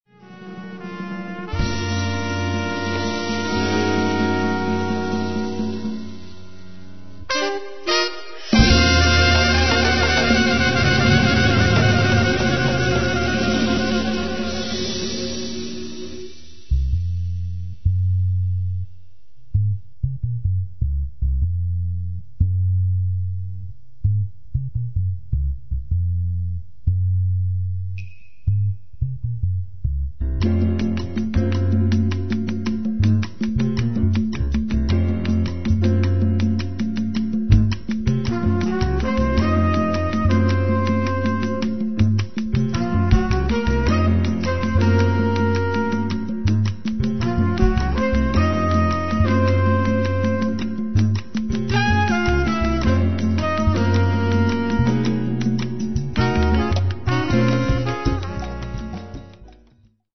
trumpet, flugelhorn, vocals
alto saxophone
drums, timbales and small percussion
quasi in stile hardbop.